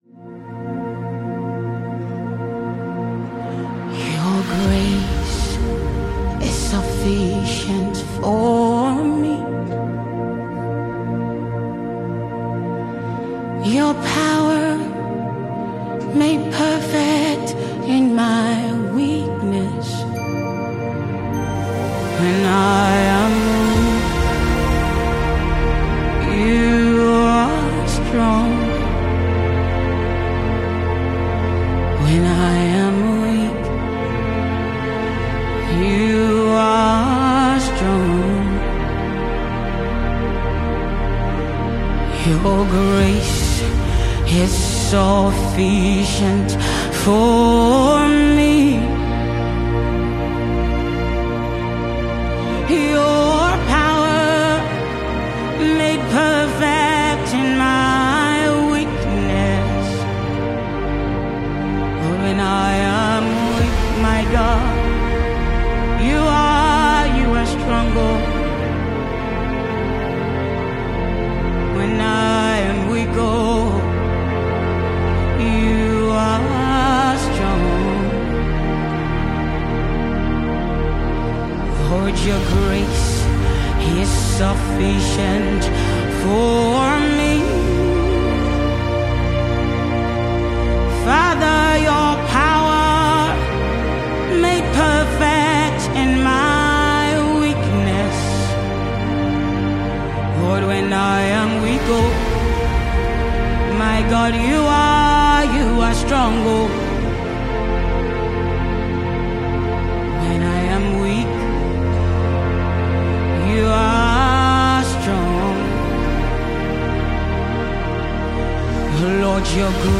Gospel